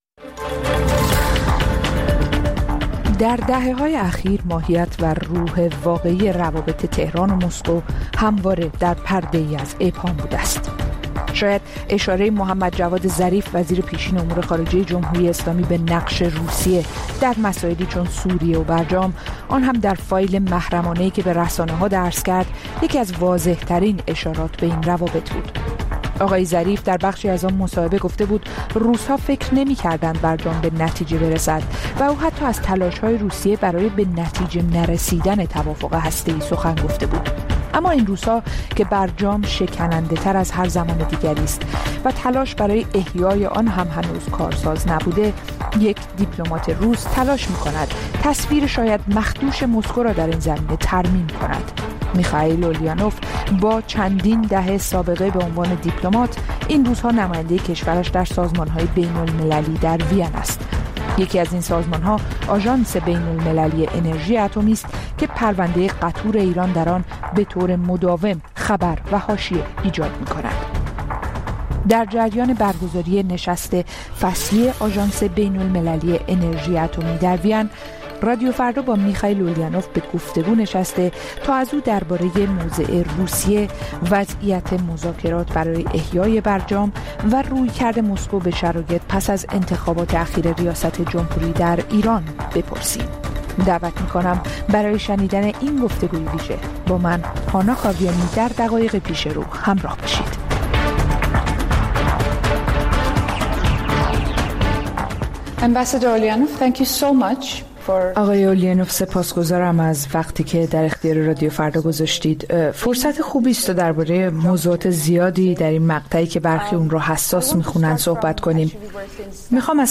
گفت‌و‌گوی اختصاصی با نماینده روسیه در آژانس بین‌المللی انرژی اتمی